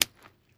STEPS Pudle, Walk 06, Single Impact.wav